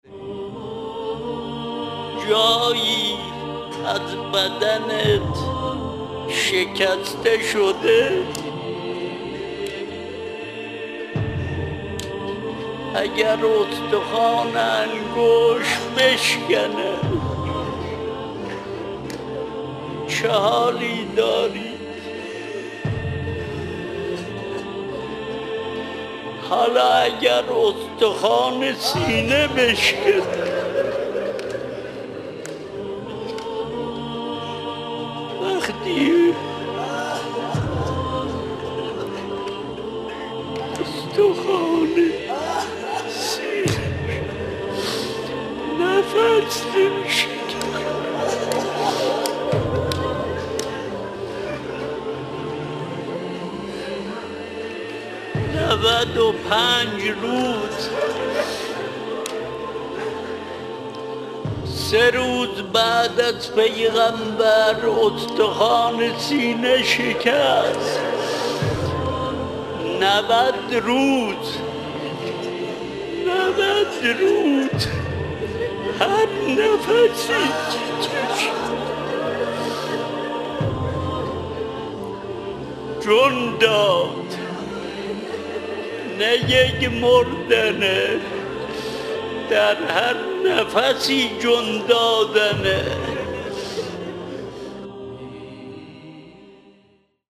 روضه حضرت زهرا.mp3
روضه-حضرت-زهرا.mp3